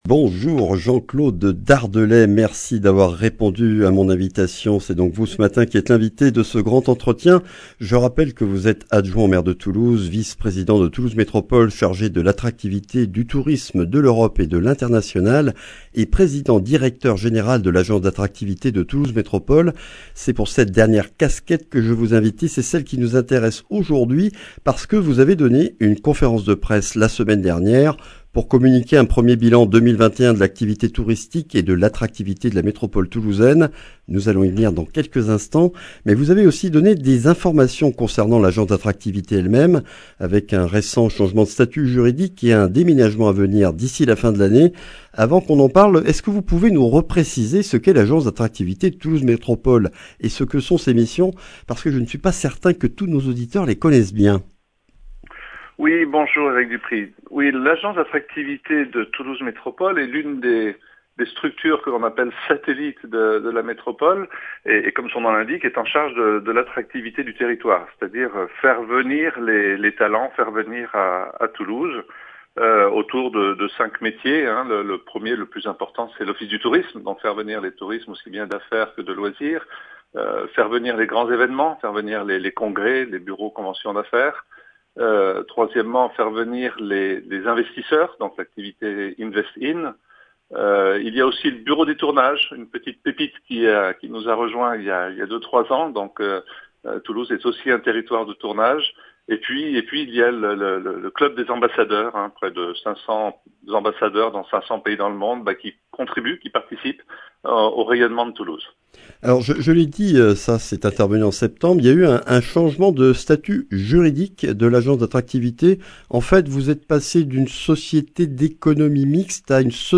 Retour dans cette matinale sur le 1er bilan 2021 de l’Agence d’attractivité avec Jean-Claude Dardelet, adjoint au maire de Toulouse, vice-président de Toulouse Métropole et président-directeur-général de l’Agence d’attractivité métropolitaine. Après une année 2020 très affectée par la crise sanitaire, constate-t-on une reprise de l’activité touristique et un regain d’attractivité du territoire métropolitain en 2021 ?